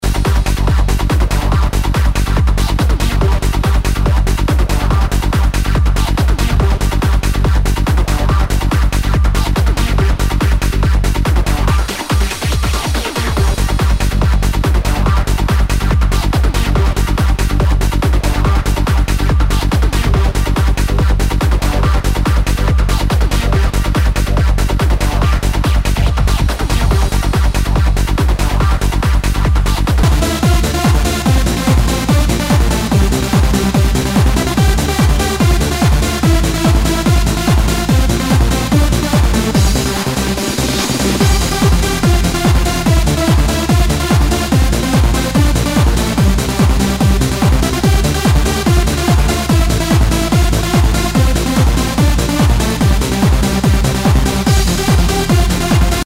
HOUSE/TECHNO/ELECTRO
ナイス！ハード・ハウス / トランス！